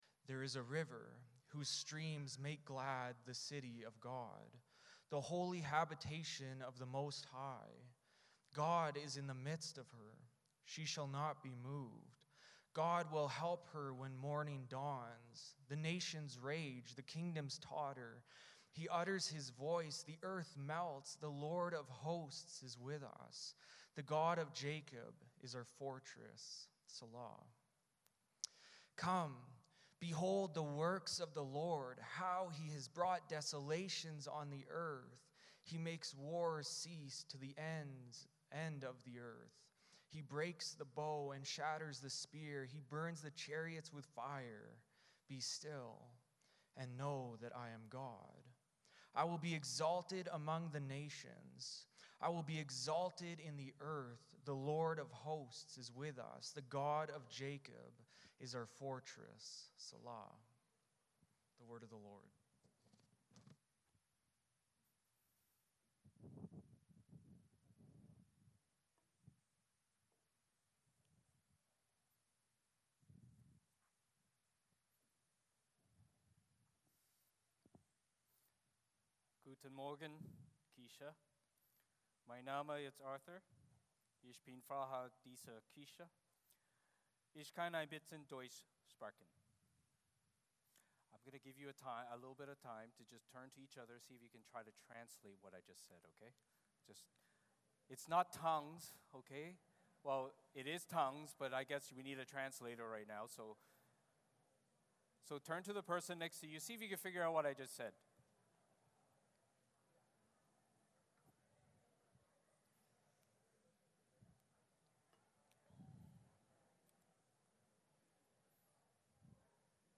Service Type: Sunday Morning Service Passage